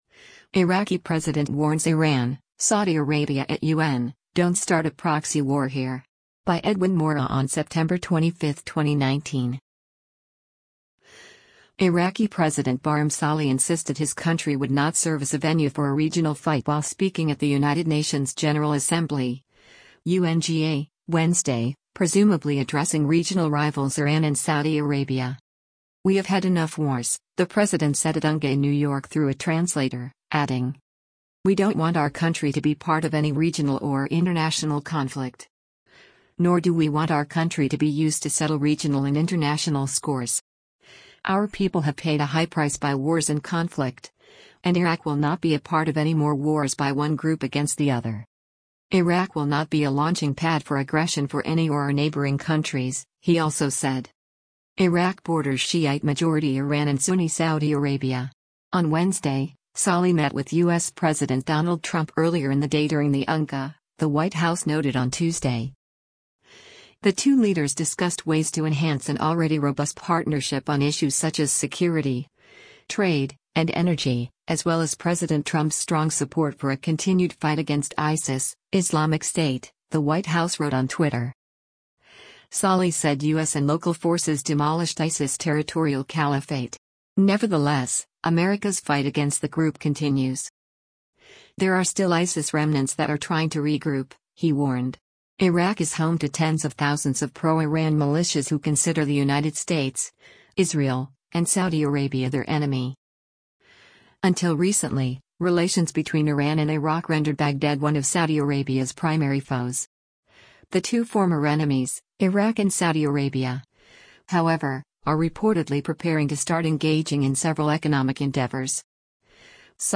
President of Iraq Barham Salih addresses the United Nations General Assembly at UN headqua
“We have had enough wars,” the President said at UNGA in New York through a translator, adding: